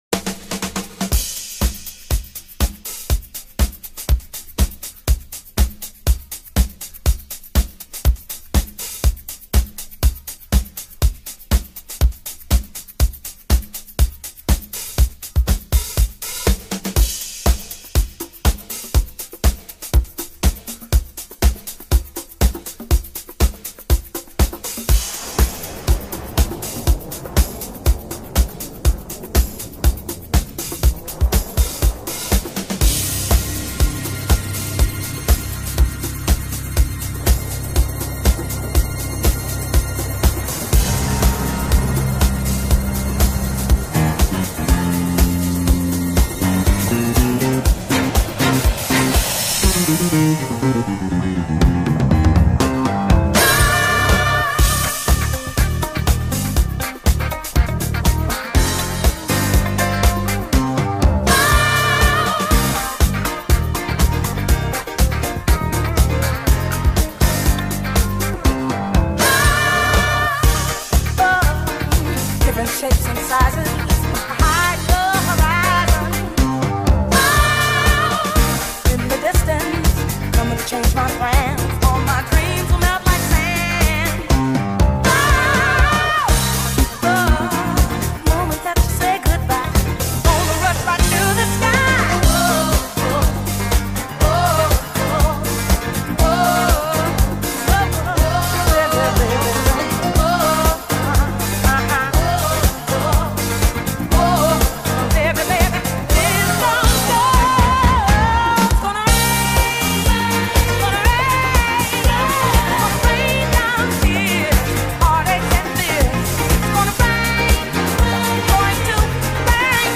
Super Disco Blend